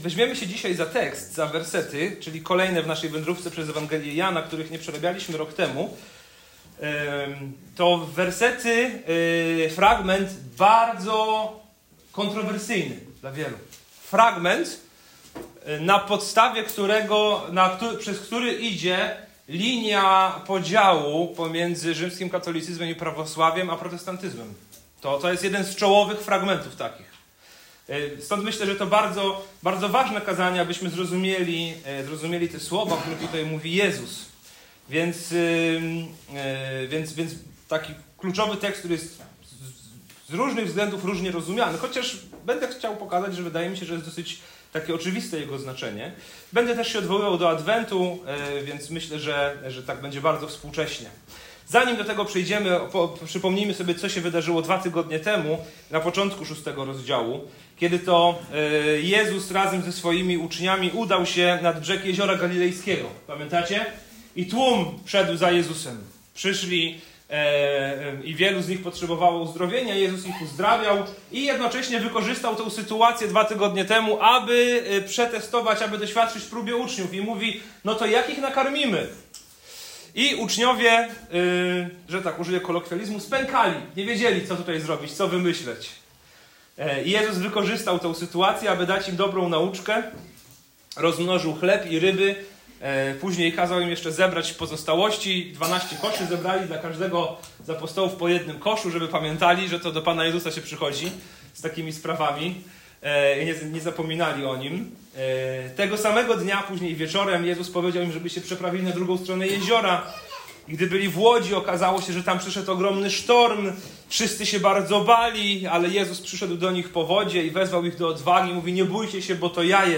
Kontrowersyjne słowa Jezusa o jedzeniu Jego ciała i piciu Jego krwi prowadzą do fundamentalnego pytania: czy Chrystus jest tylko elementem religijnej tradycji, czy codziennym źródłem naszego życia. To kazanie pomaga zrozumieć sens tych słów, konfrontuje powierzchowną wiarę i zaprasza do prawdziwego „karmienia się” Chrystusem przez wiarę.